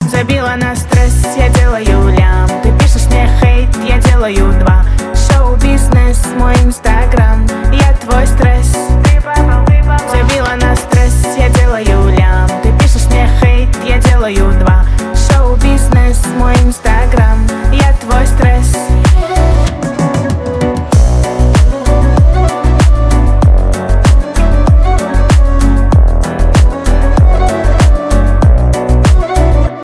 cover кавер